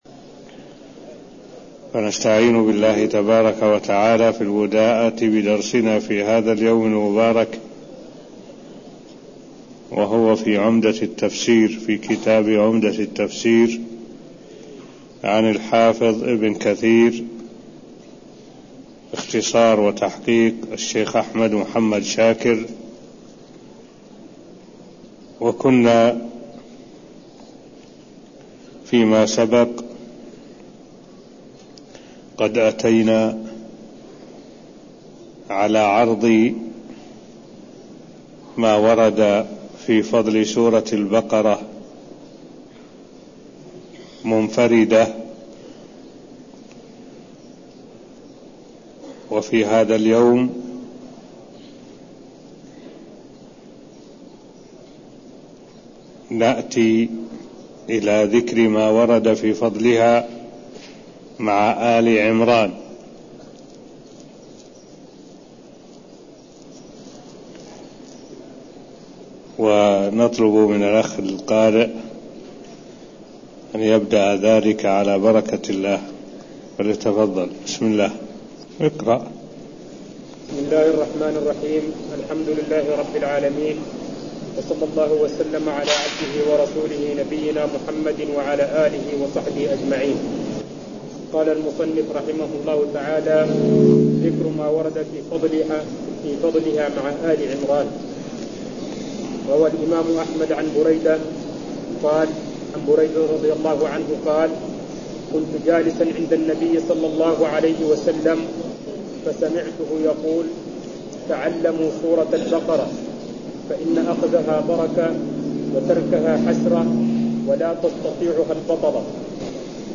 المكان: المسجد النبوي الشيخ: معالي الشيخ الدكتور صالح بن عبد الله العبود معالي الشيخ الدكتور صالح بن عبد الله العبود تفسير سورة البقرة ذكر ما ورد في فضلها مع آل عمران (0015)